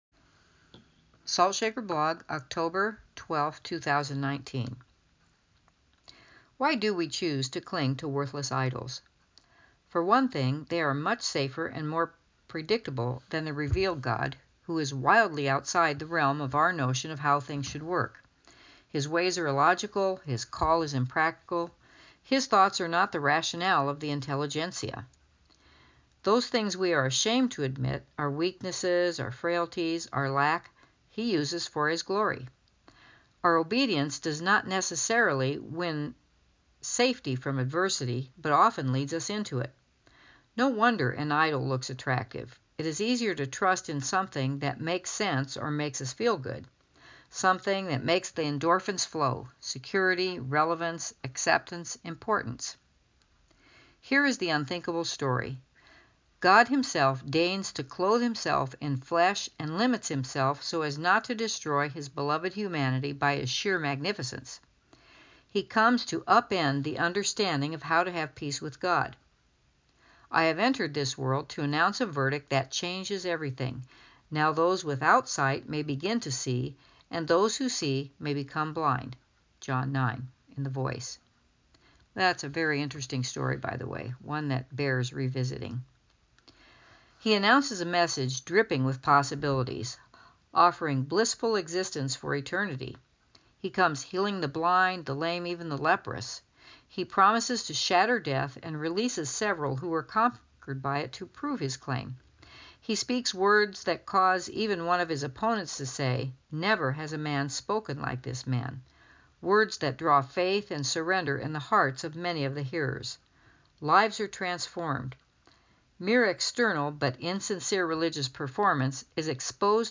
Audio version author read click here